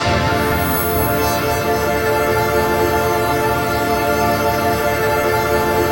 DM PAD1-07.wav